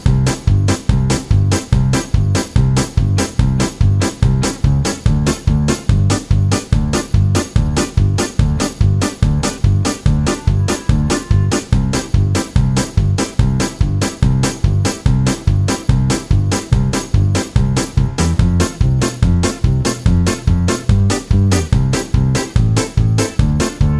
Comedy/Novelty